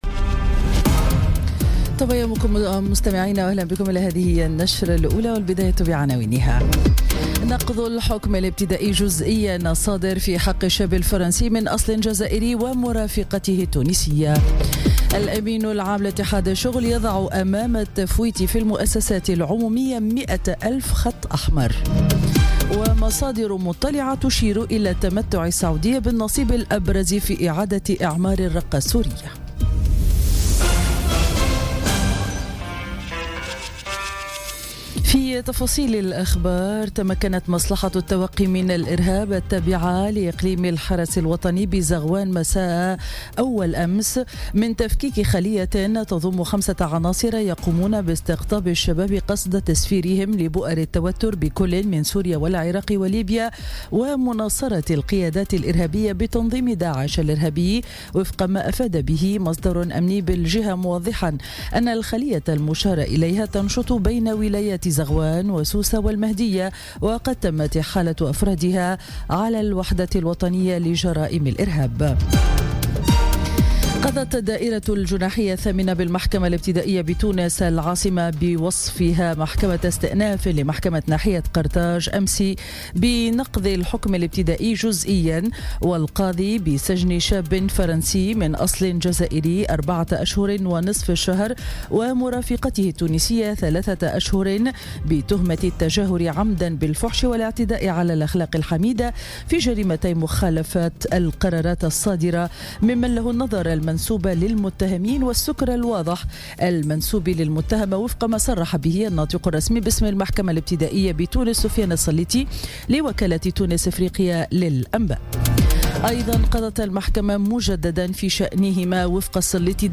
نشرة أخبار السابعة صباحا ليوم الخميس 19 أكتوبر 2017